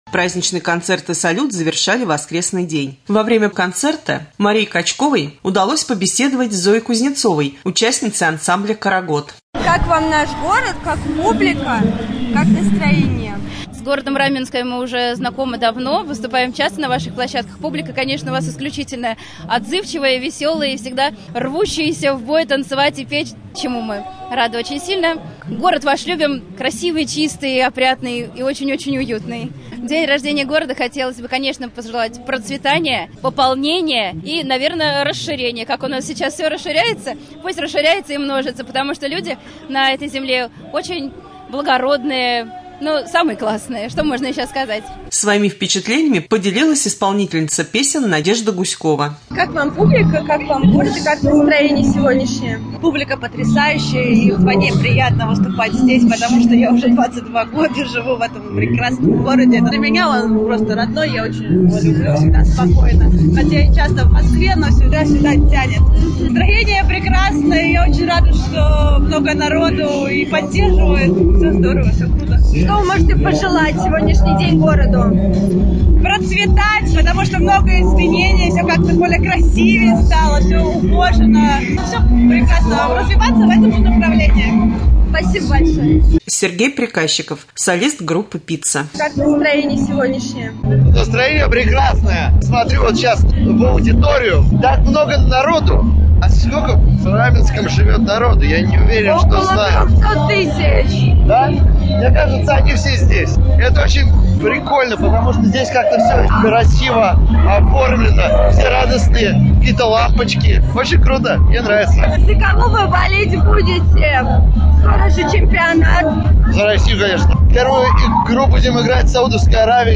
пообщалась с артистами на праздничном концерте.